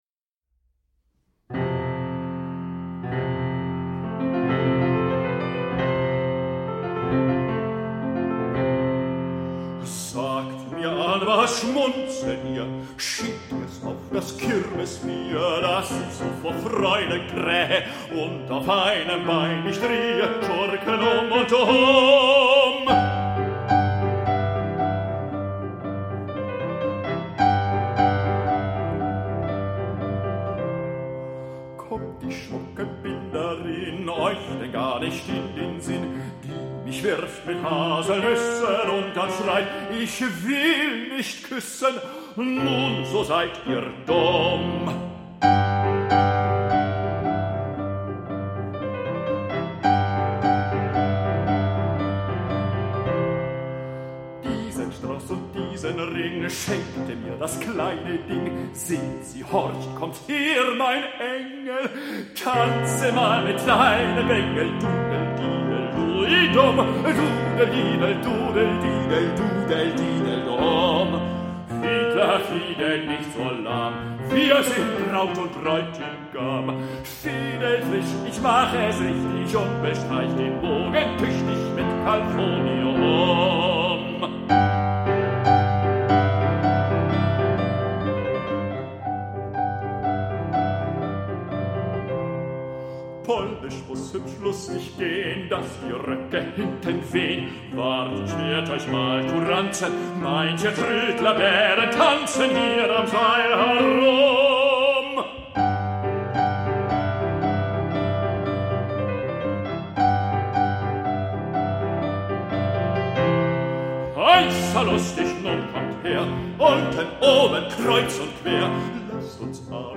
bewegt, heiter